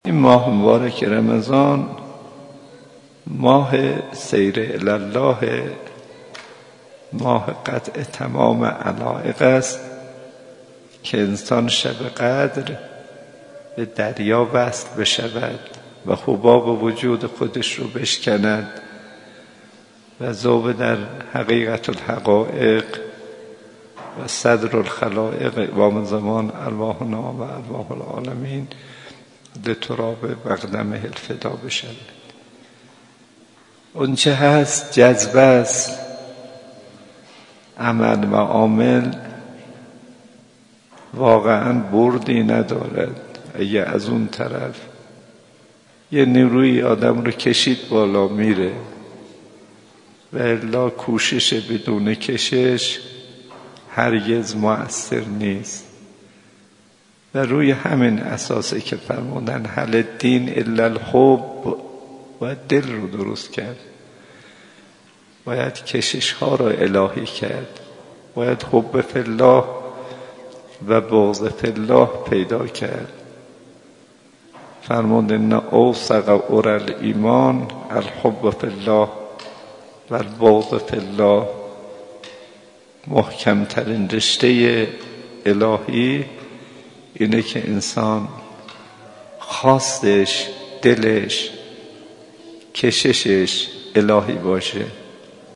به گزارش خبرنگار خبرگزاری رسا، آیت‌الله کاظم صدیقی امام جمعه موقت تهران، شب گذشته در مدرسه علمیه امام خمینی(ره)، گفت: رمضان، ماه انقطاع الی الله است.